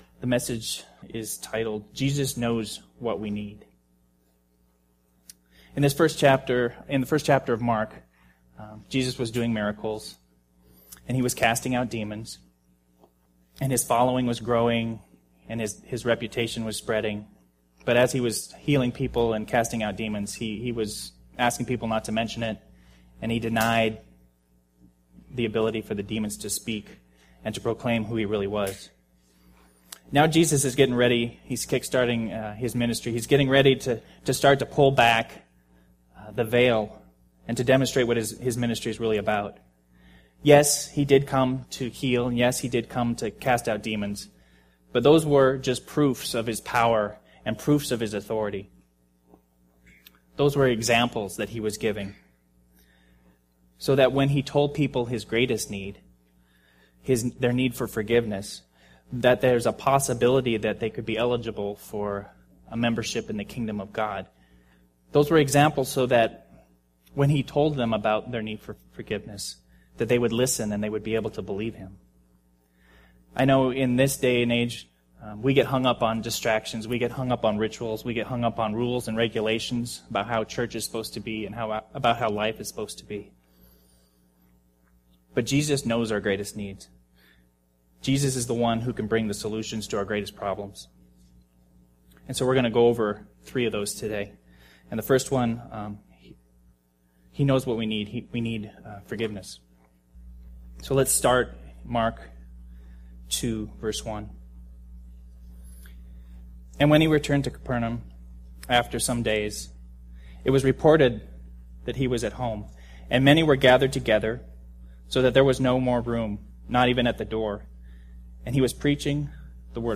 Mark Passage: Mark 2:1-28 Service: Men's Bible Fellowship Mark 2 « United We Stand